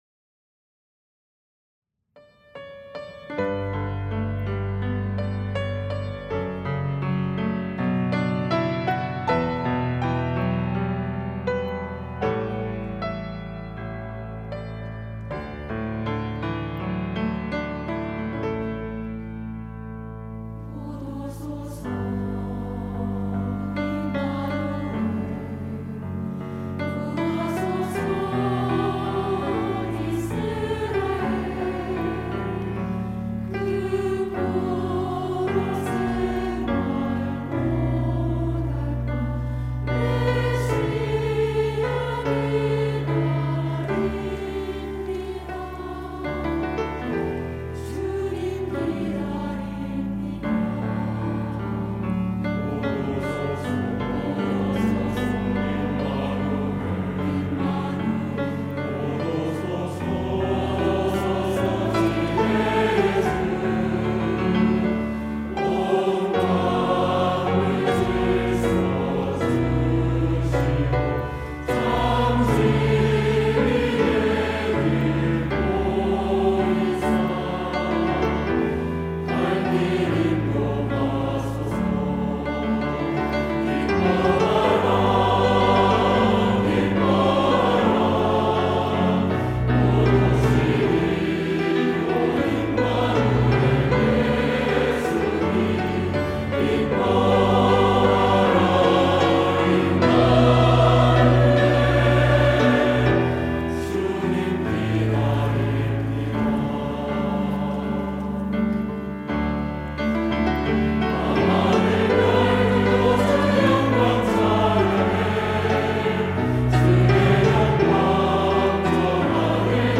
할렐루야(주일2부) - 곧 오소서 임마누엘
찬양대